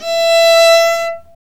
Index of /90_sSampleCDs/Roland - String Master Series/STR_Viola Solo/STR_Vla3 % + dyn
STR VIOLA 0F.wav